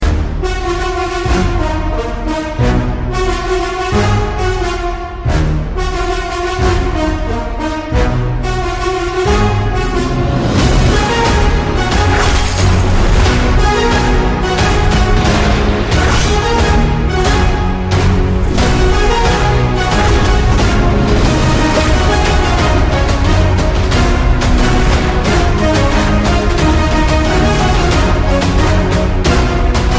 With a combination of traditional and modern sounds
If you are looking for a song full of energy and emotion